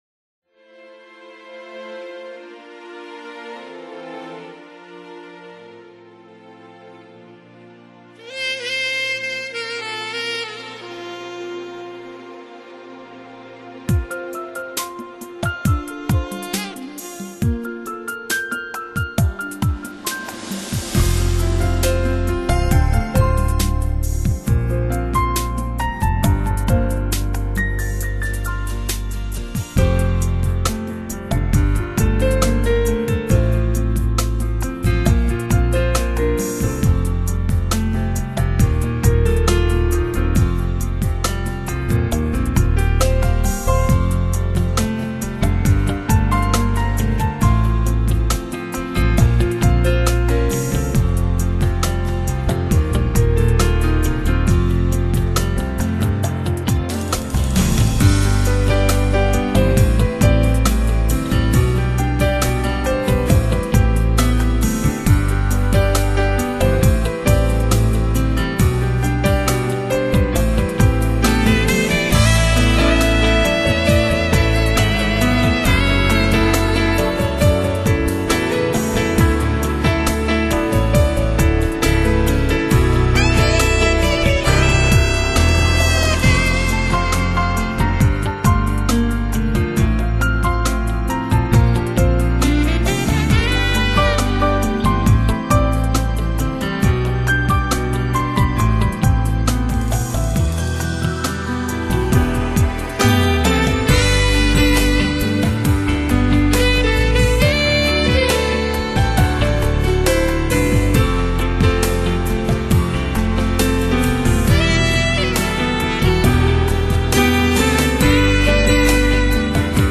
风  格：New Age(新世纪)
他擅長以優美的旋律描述自然美景和浪漫心境，鋼琴音色溫暖，演奏有如行